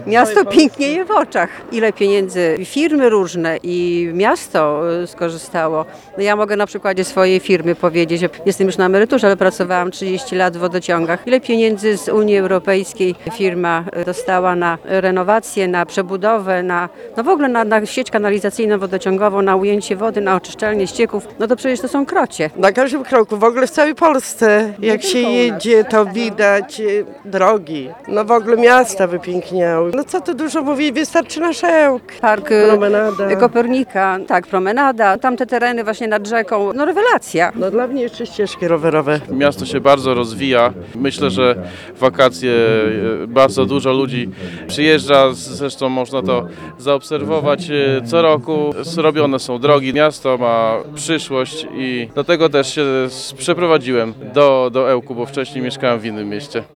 Czy 15-lecie wstąpienia Polski do Unii to dobry powód, by świętować? Ełczanie, którzy uczestniczyli w dzisiejszej uroczystości twierdzą, że tak.